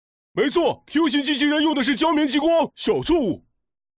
动漫语音
斯坦： 17岁左右，憨厚，略带鼻音的音色，声优通过带有鼻音带出憨傻感觉的表演，演绎出如同一个头脑简单四肢发达的生动形象。